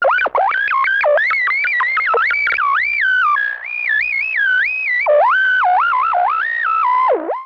Static.mp3